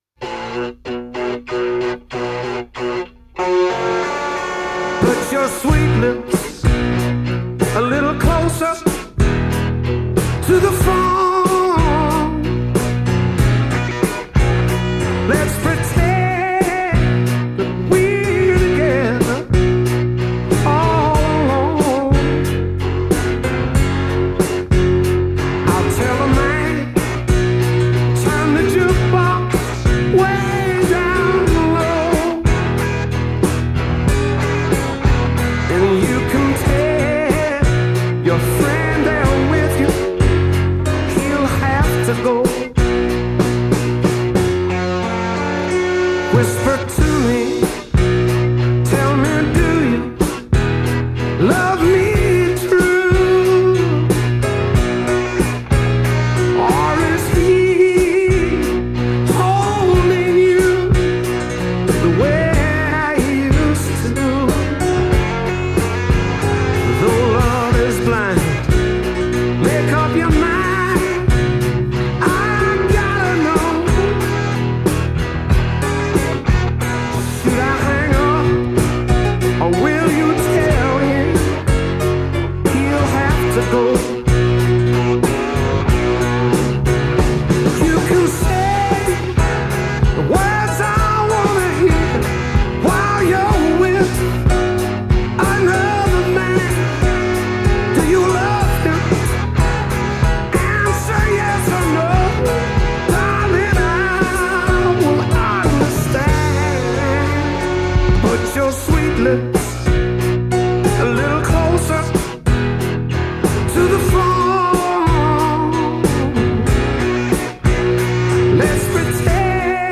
Rock.